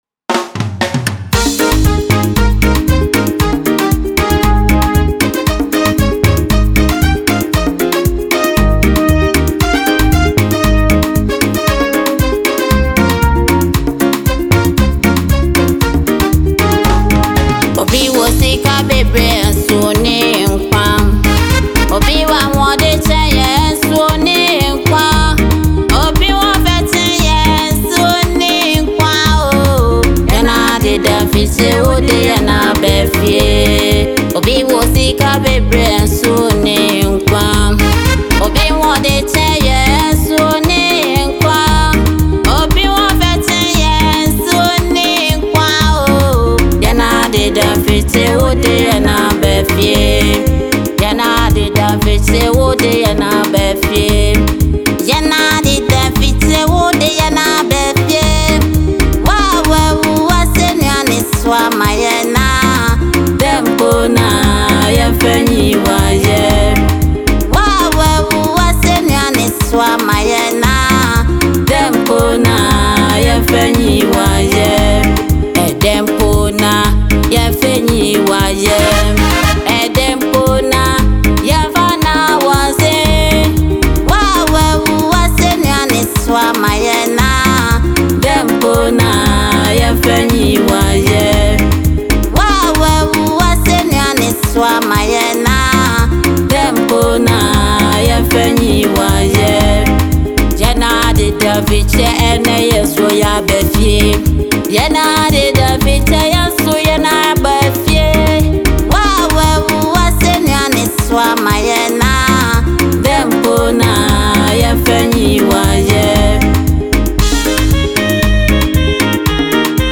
soul-stirring gospel track